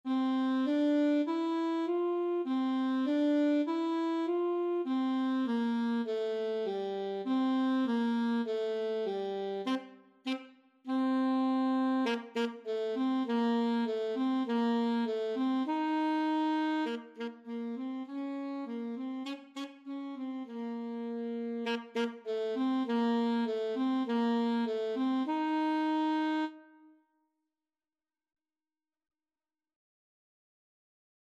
4/4 (View more 4/4 Music)
G4-F5
Saxophone  (View more Beginners Saxophone Music)
Classical (View more Classical Saxophone Music)